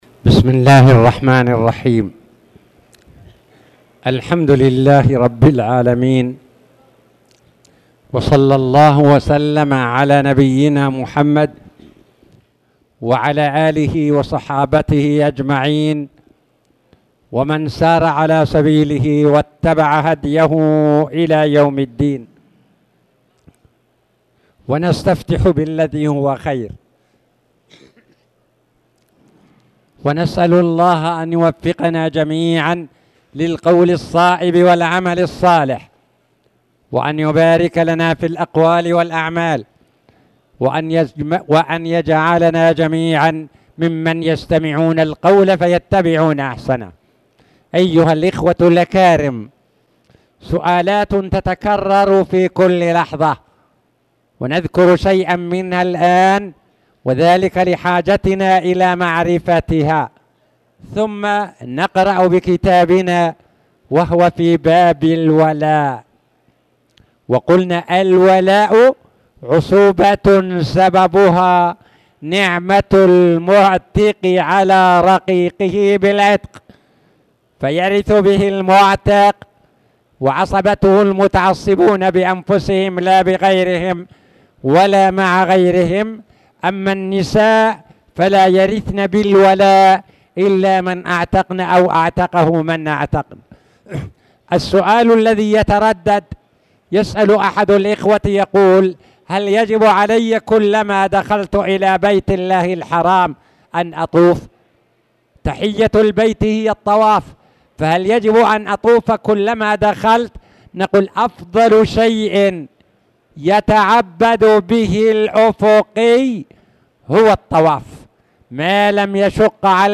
تاريخ النشر ٢٥ ذو القعدة ١٤٣٧ هـ المكان: المسجد الحرام الشيخ